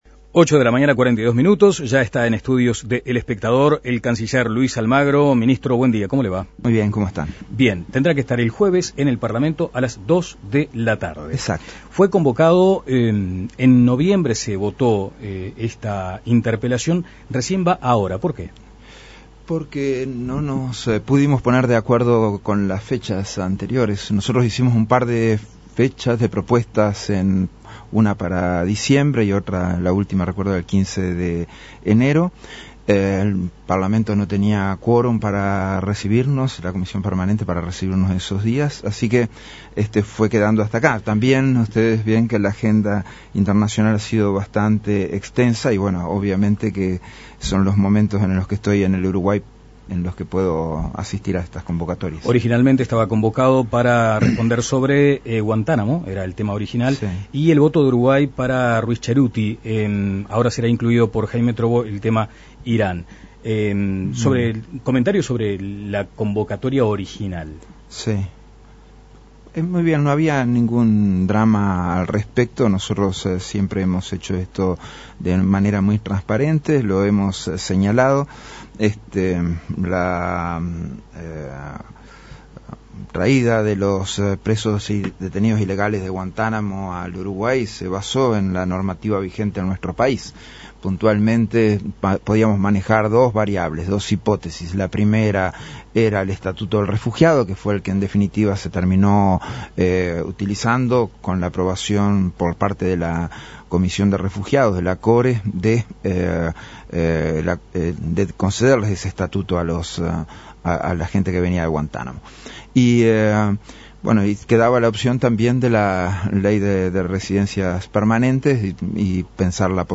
Descargar Audio no soportado Entrevista a Luis Almagro